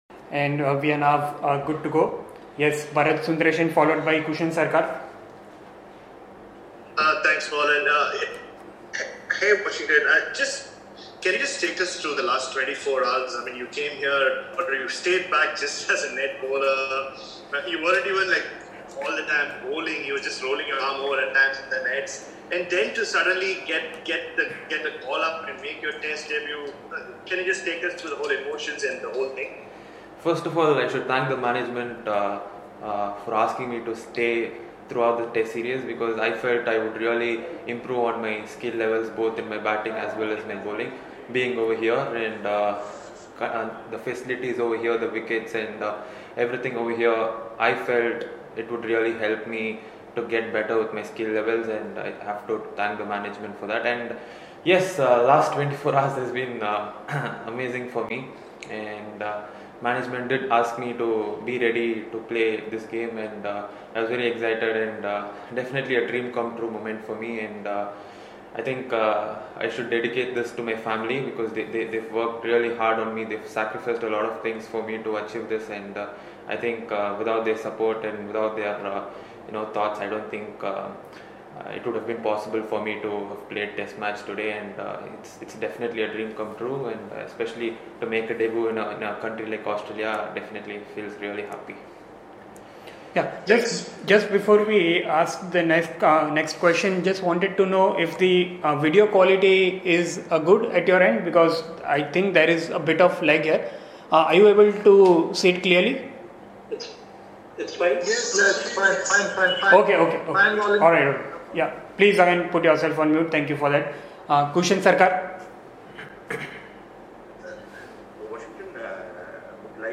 Washington Sundar addressed a virtual press conference after the first day’s play of the 4th Border-Gavaskar Test against Australia in Brisbane.